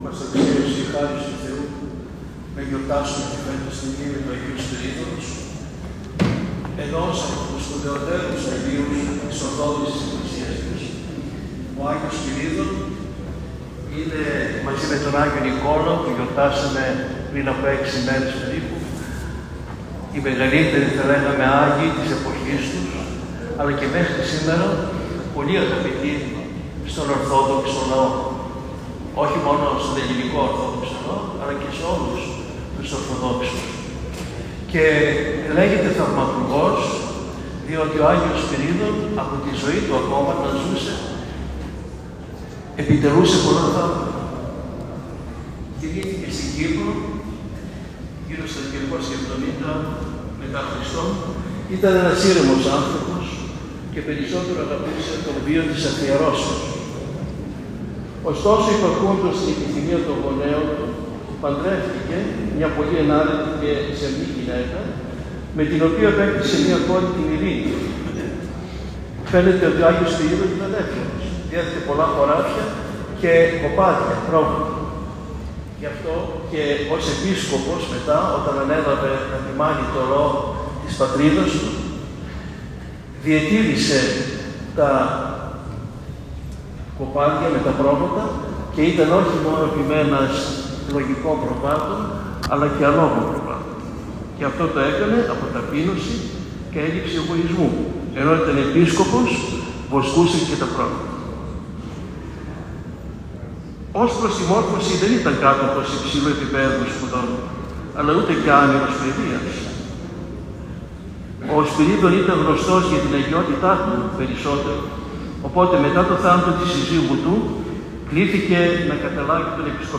Στην ακολουθία του Αρχιερατικού Εσπερινού χοροστάτησε με την ευλογία του Παναγιωτάτου Μητροπολίτου Θεσσαλονίκης κ. Φιλοθέου, ο Επίσκοπος Ρηγίου κ. Ειρηναίος.
κήρυγμα